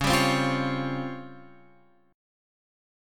C# Major 7th Flat 5th